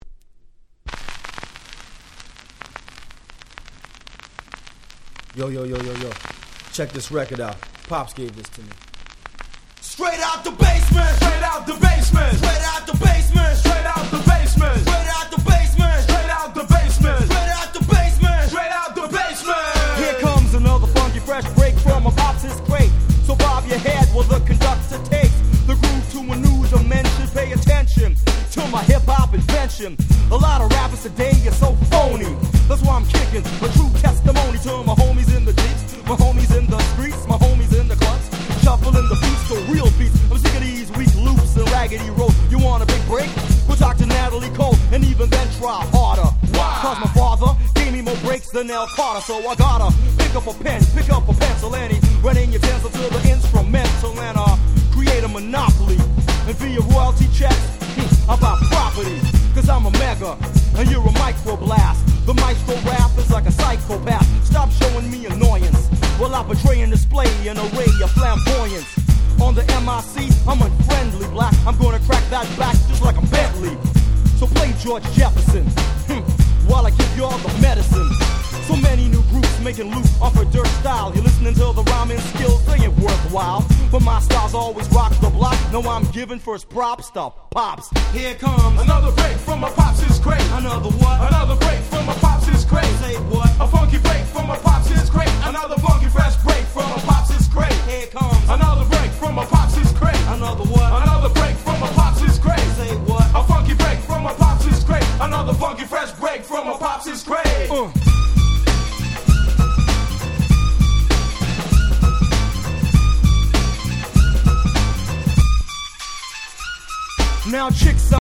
92' Very Nice Boom Bap / Hip Hop !!
90's ブーンバップ